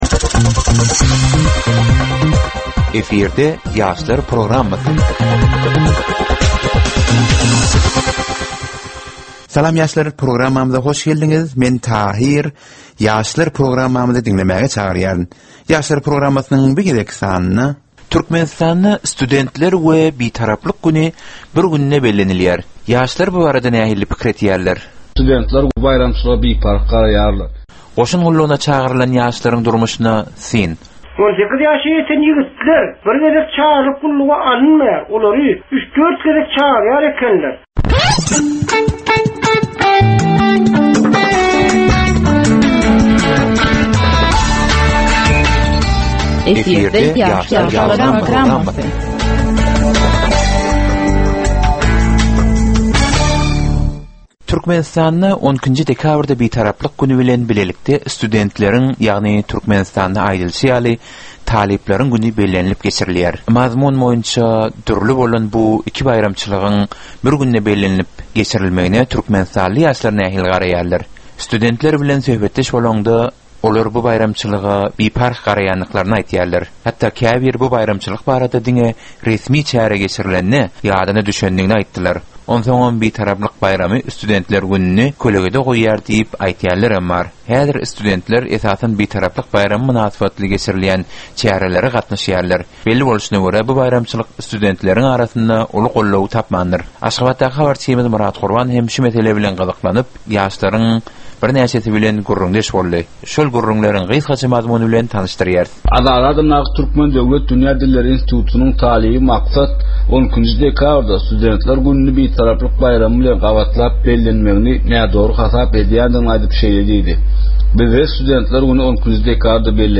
Türkmen we halkara yaşlarynyň durmuşyna degişli derwaýys meselelere we täzeliklere bagyşlanylyp taýýarlanylýan 15 minutlyk ýörite gepleşik. Bu gepleşikde ýaşlaryň durmuşyna degişli dürli täzelikler we derwaýys meseleler barada maglumatlar, synlar, bu meseleler boýunça adaty ýaslaryň, synçylaryň we bilermenleriň pikrileri, teklipleri we diskussiýalary berilýär. Gepleşigiň dowamynda aýdym-sazlar hem eşitdirilýär.